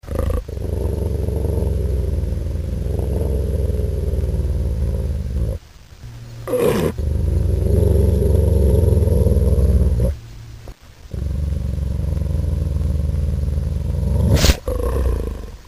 wolf-growling.mp3